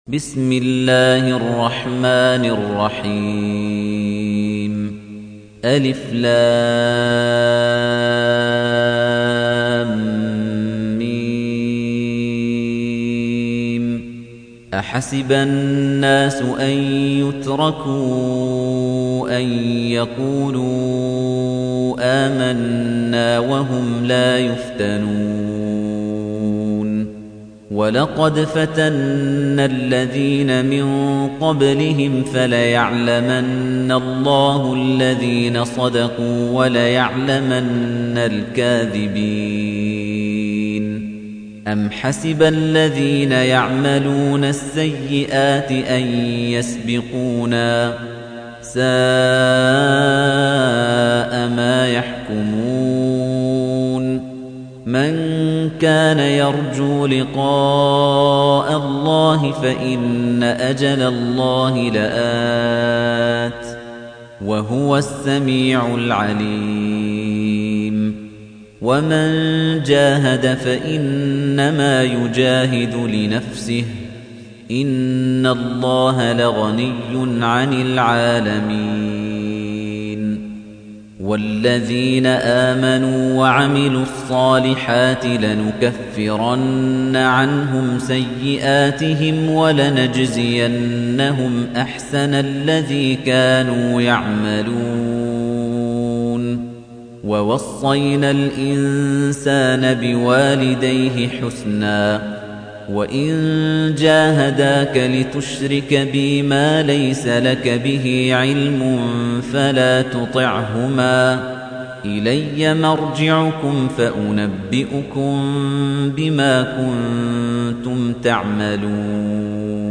تحميل : 29. سورة العنكبوت / القارئ خليفة الطنيجي / القرآن الكريم / موقع يا حسين